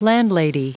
Prononciation du mot landlady en anglais (fichier audio)
Prononciation du mot : landlady